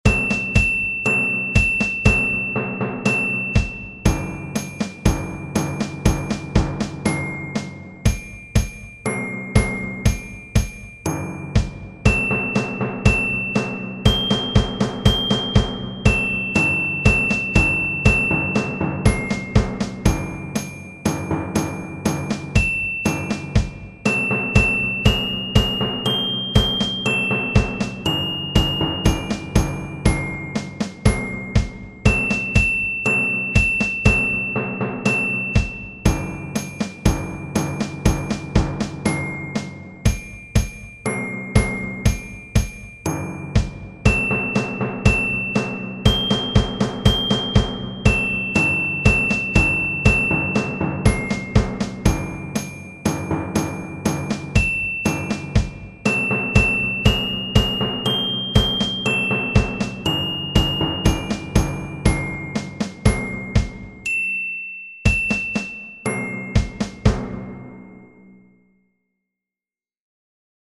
Percussion ensemble percussion quartet percussion trio